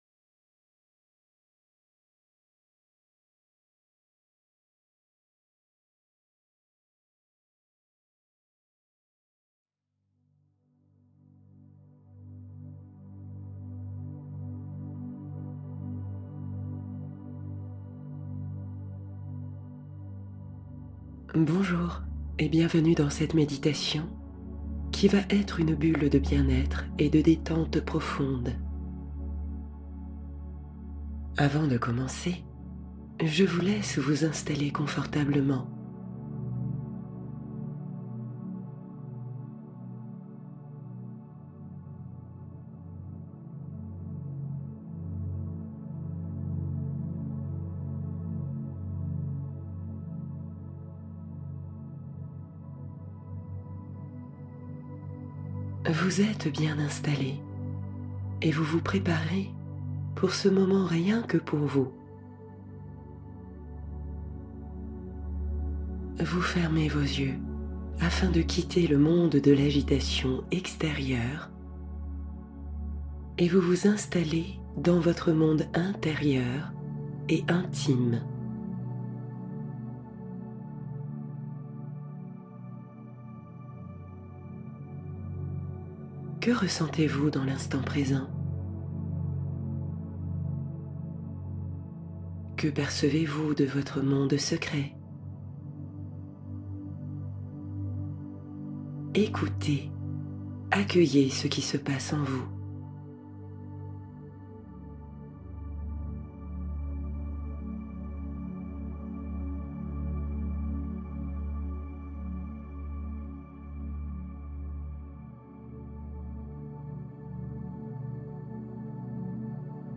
Scan corporel du soir — Message de la mer et nuit paisible